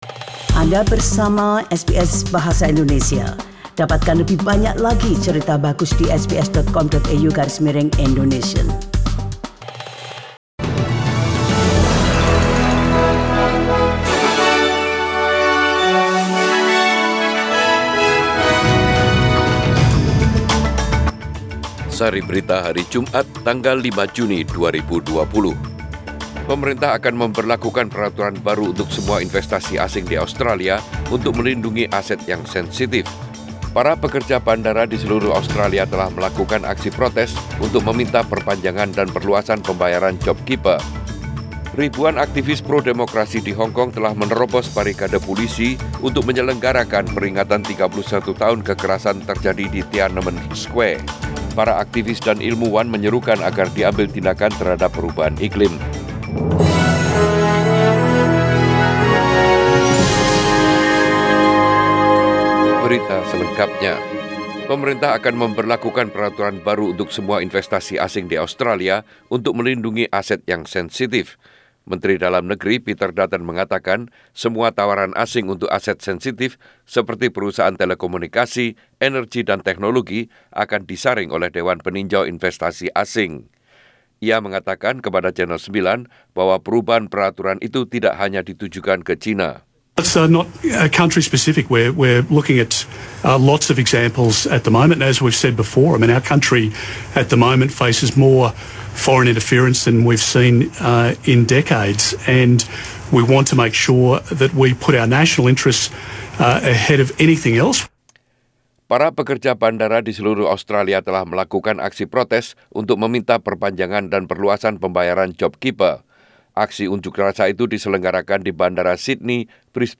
SBS Radio News in bahasa Indonesia